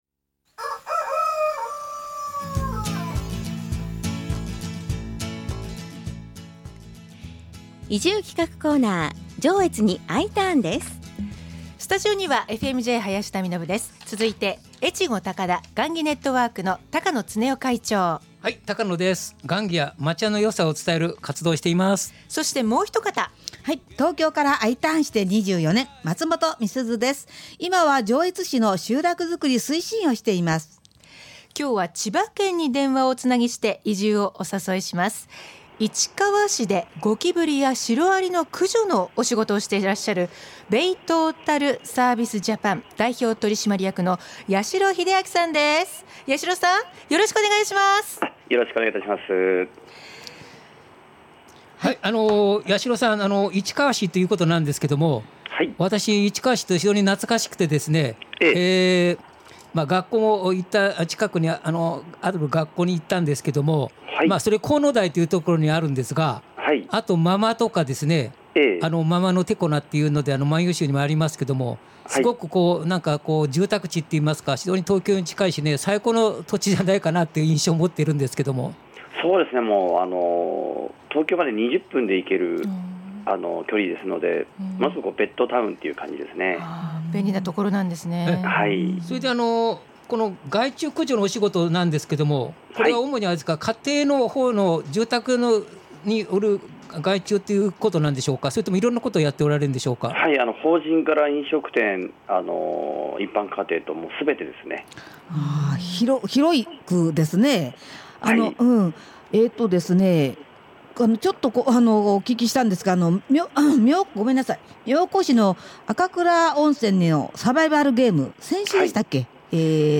FM-Jのスタジオから移住をお誘いするコーナー。